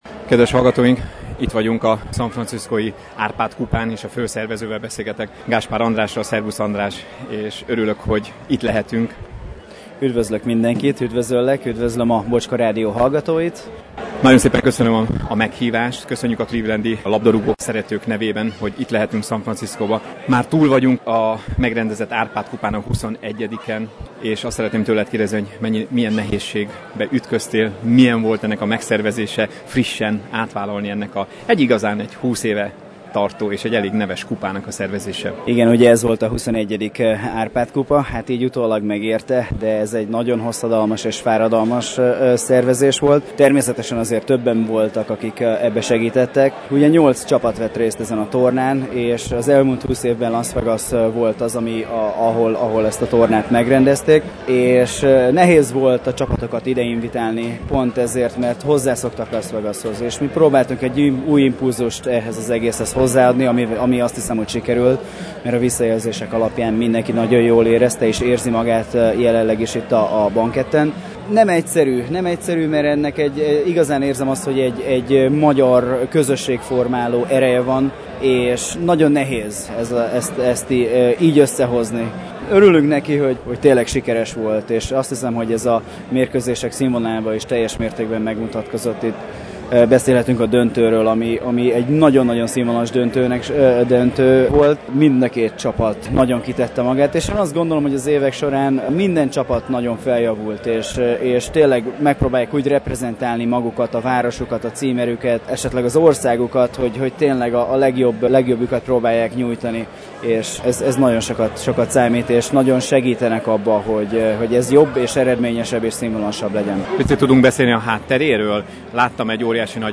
(Az interjú itt is meghallgatható a vasárnapi rádióadásunk után).